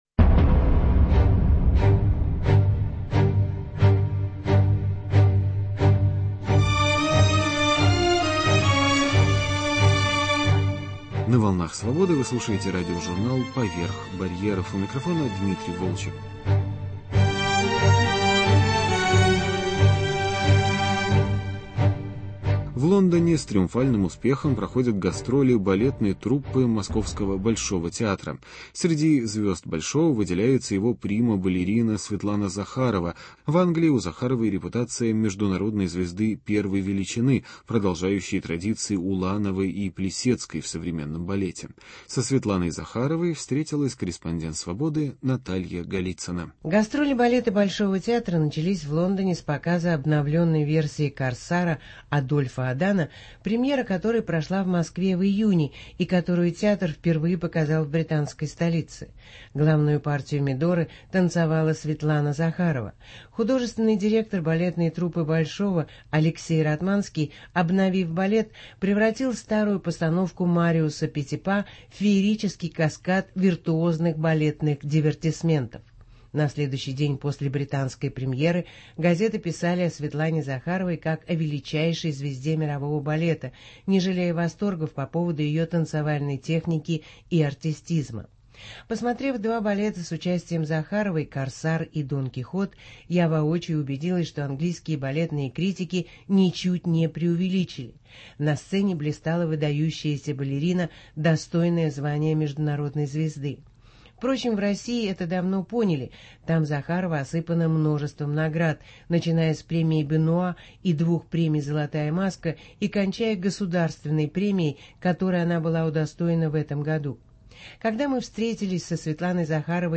Интервью с балериной Светланой Захаровой.